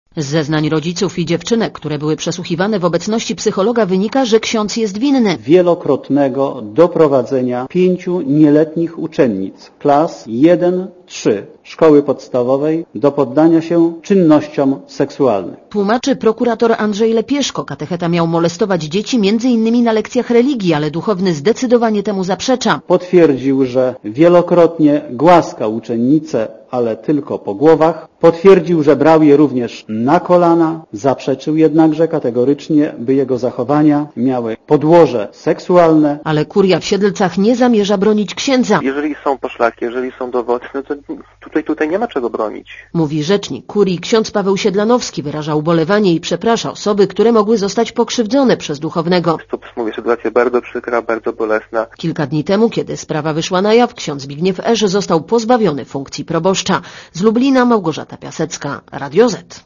Posłuchaj relacji reporterki Radia Zet (231 KB)